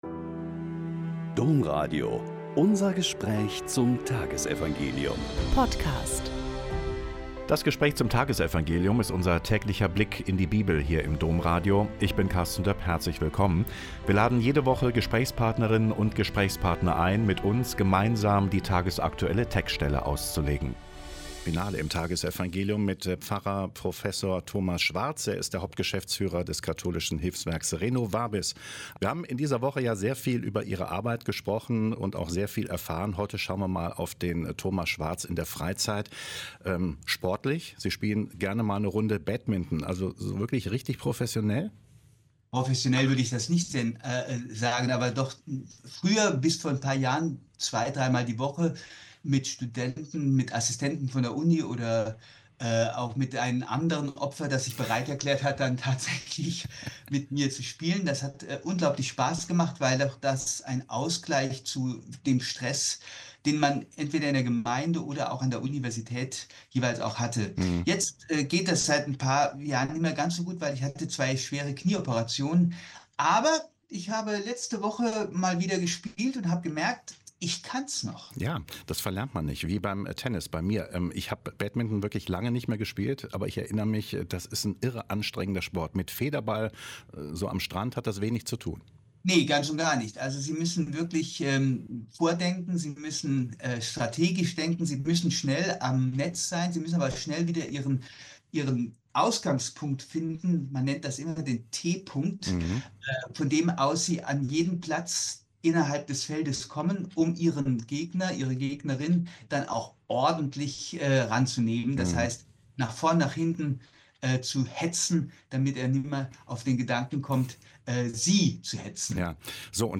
Mt 5,43-48 - Gespräch